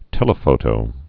(tĕlə-fōtō)